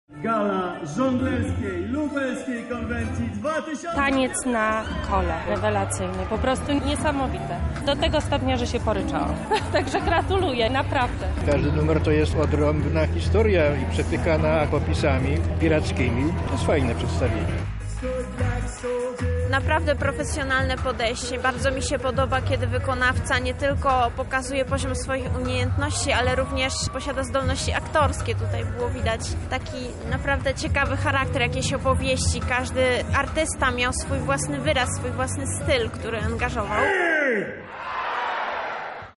żelka-relacja.mp3